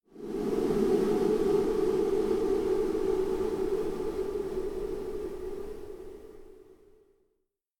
whoosh_long.ogg